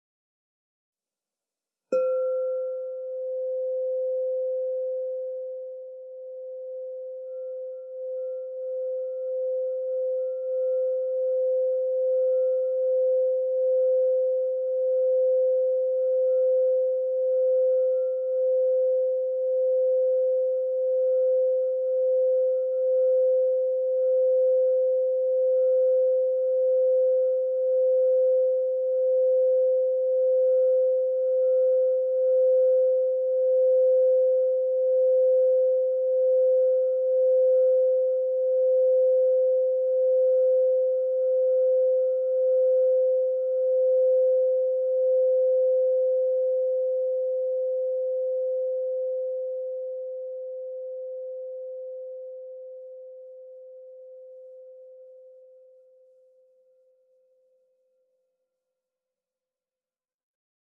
Meinl Sonic Energy 8" white-frosted Crystal Singing Bowl C5, 432 Hz, Root Chakra (CSB8C5)
The white-frosted Meinl Sonic Energy Crystal Singing Bowls made of high-purity quartz create a very pleasant aura with their sound and design.